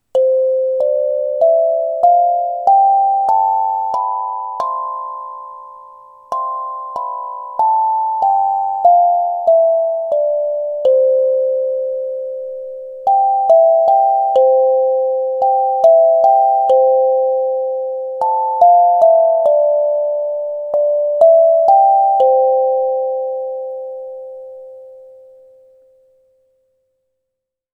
NINO Percussion Steel Tongue Drum - Blue (NINO981)
Size: 7” with 8 TonguesTuning: c’, d’, e’, f’, g’, a‘, b’, c’’Feature 1: Great for classrooms or to play at home for funFeature 2: Pre-tuned C-Major …
Its pre-tuned scale means there are no wrong notes, and the sound is mellow and soothing (like a soft steel pan drum). Includes a transportation bag and two soft rubber mallets for a full and tranquil tone.